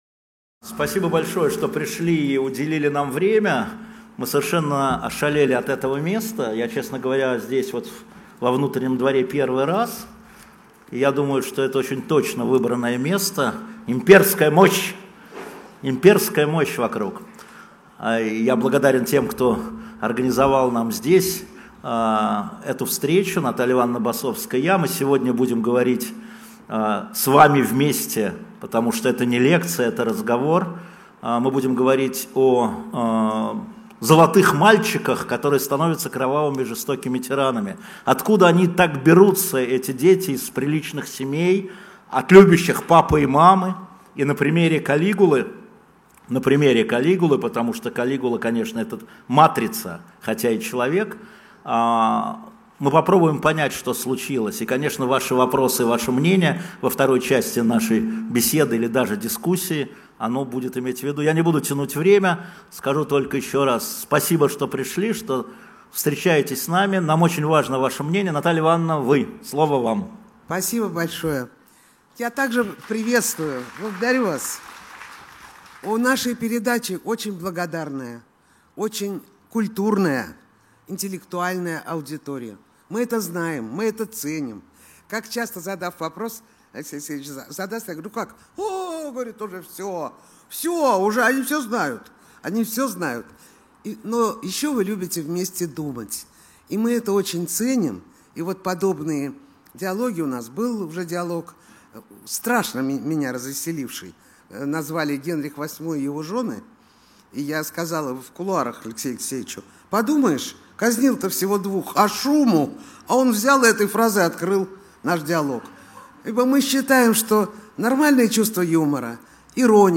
26 июля 2017 года в Петровском путевом дворце состоялась презентация нового номера журнала «Дилетант». Главную тему про Калигулу представили Алексей Венедиктов и Наталия Басовская.